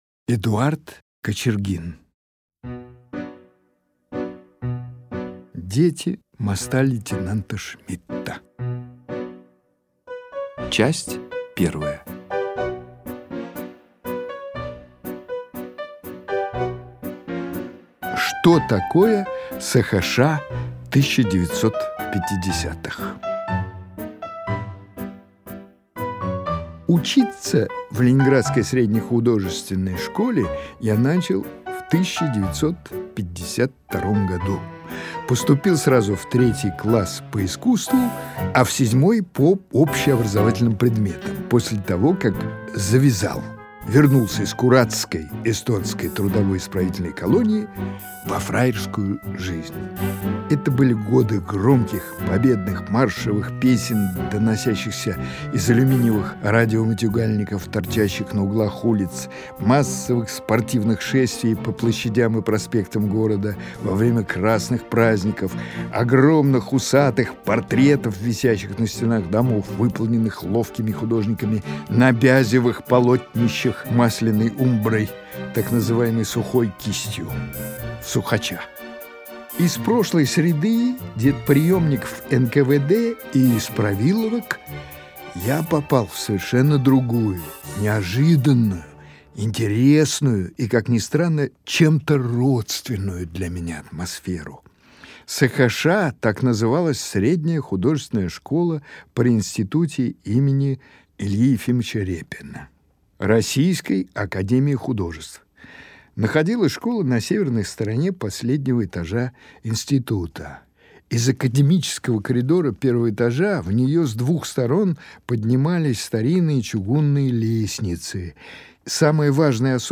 Литературные чтения (20:45)